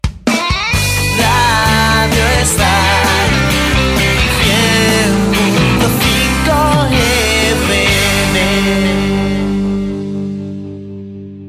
Indicatiu de l' emissora